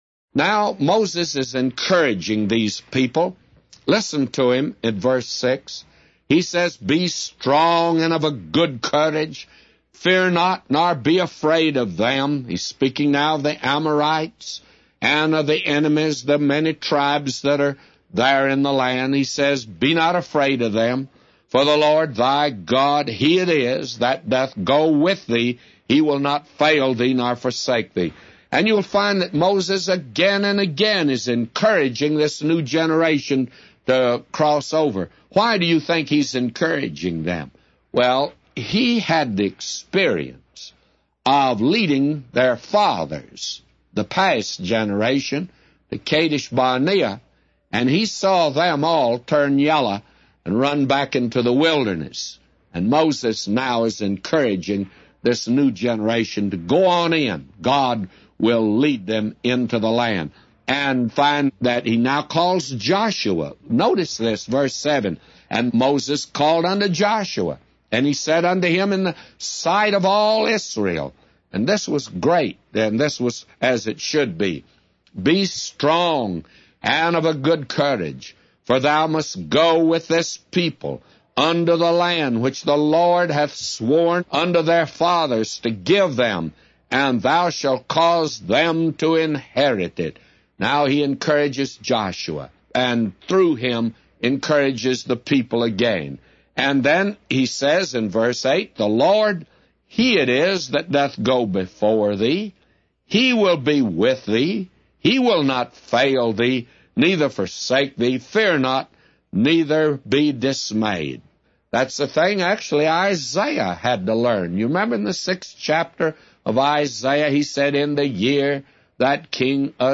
A Commentary By J Vernon MCgee For Deuteronomy 31:6-999